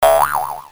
badboom.mp3